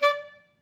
Clarinet
DCClar_stac_D4_v3_rr1_sum.wav